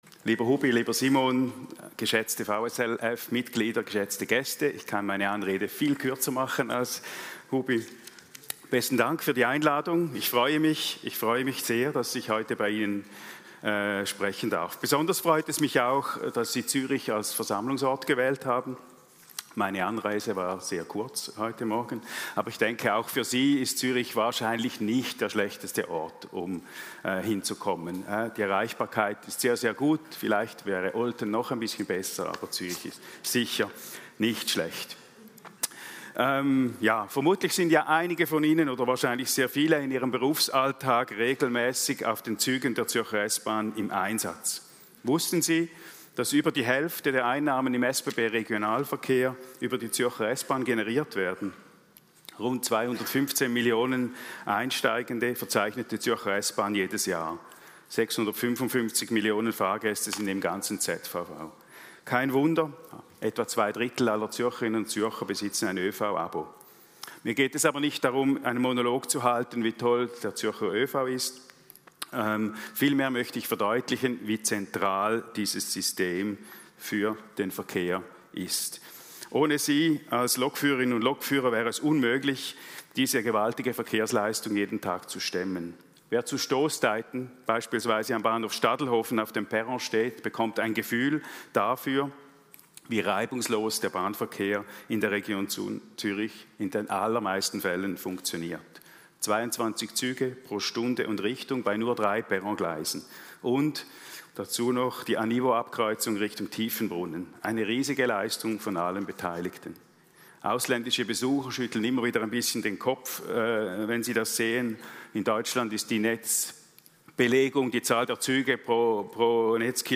67a AG 2024 Zurigo / 15 marzo 2025
Rede